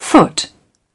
3. foot  /fʊt/ : 1 bàn chân